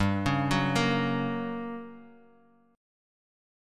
Gb/G chord
Gb-Major-G-3,4,4,3,x,x-8.m4a